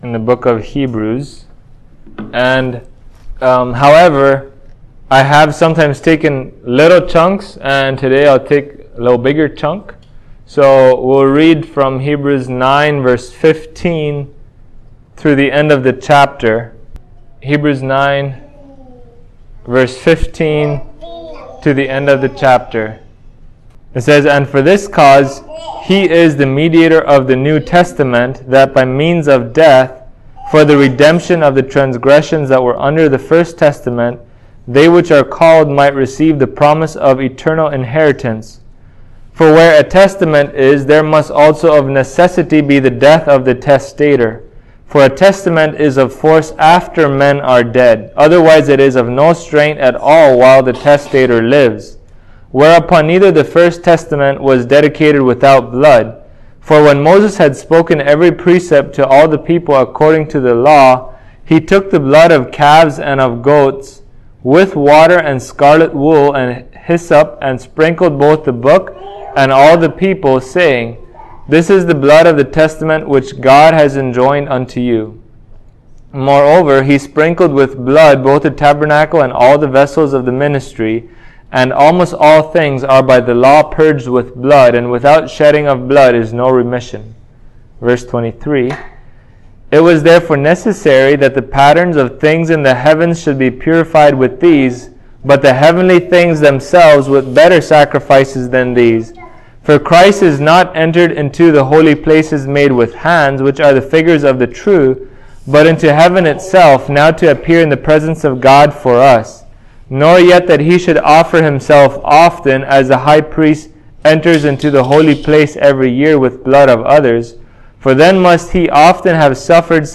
Find out as you listen to this exposition of Hebrews 9 at Grace Bible Community Church.
Hebrews 9:15-28 Service Type: Sunday Morning What does it mean that Believers have Christ as their mediator?